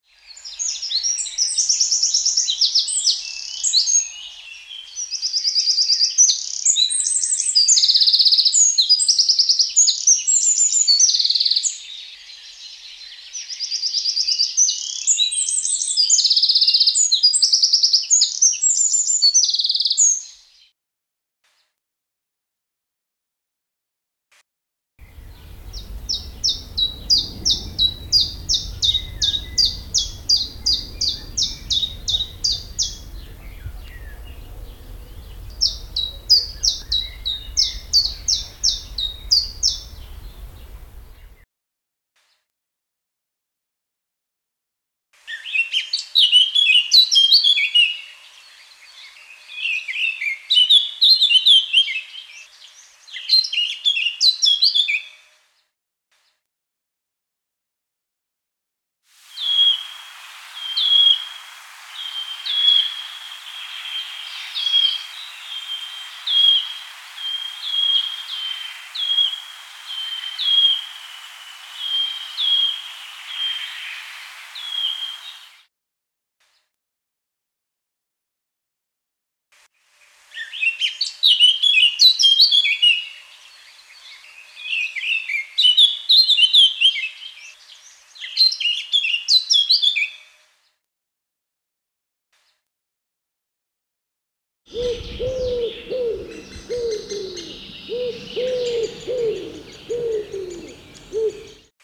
Vogelstimmen